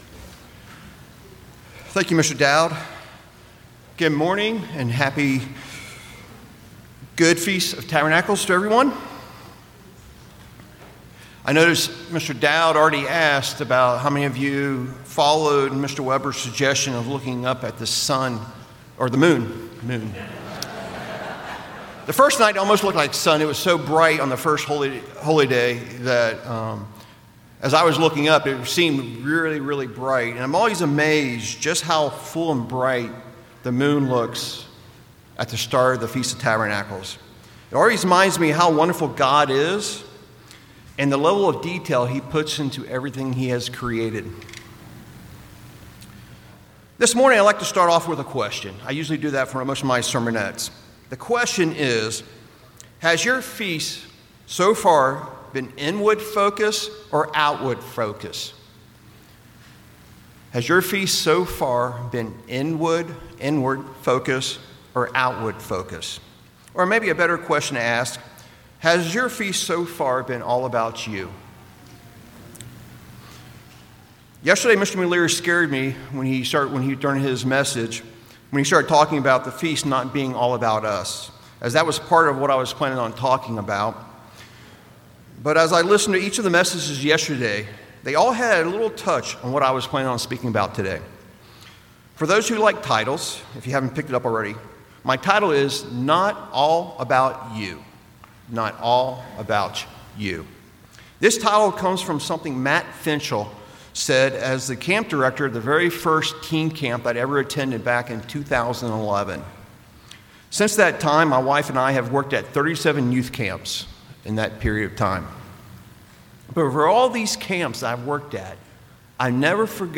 Sermons
Given in Temecula, California